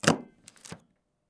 Máquina de hacer agujeros 1